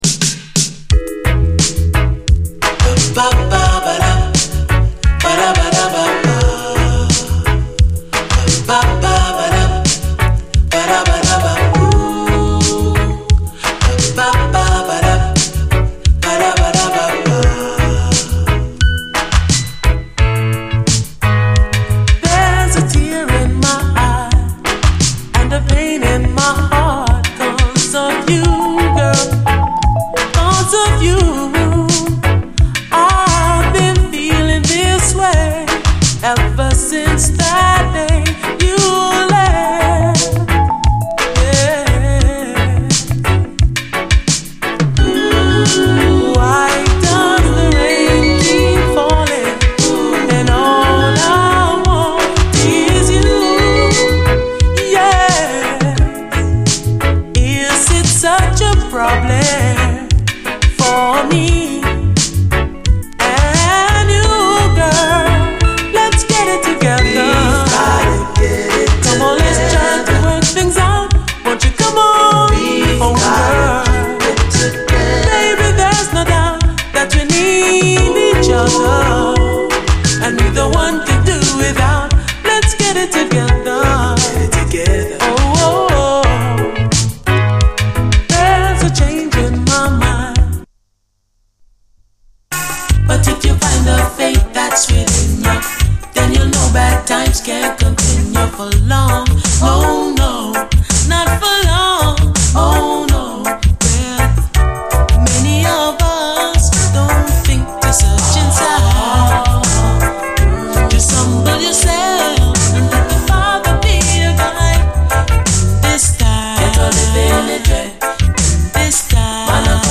REGGAE
優しいビューティフル・メロウ・ラヴァーズだらけで全編最高！